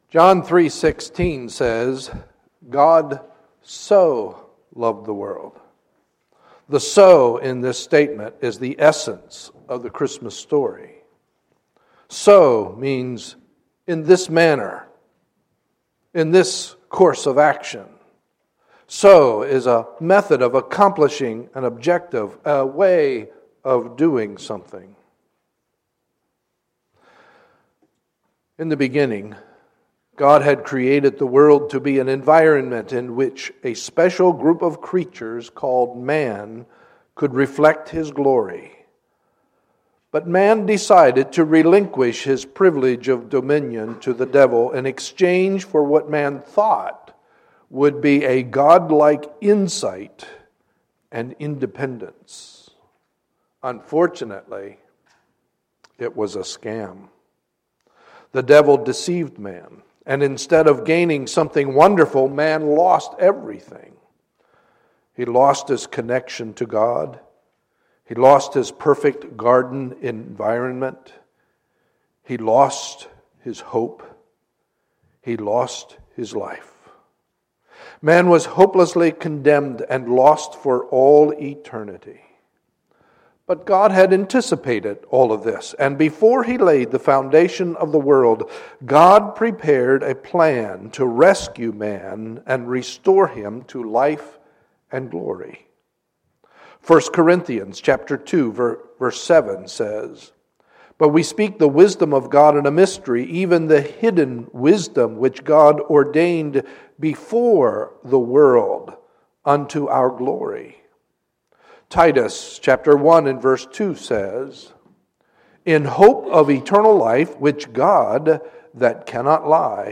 2015 Children’s Christmas Program
North Hills Bible Church Children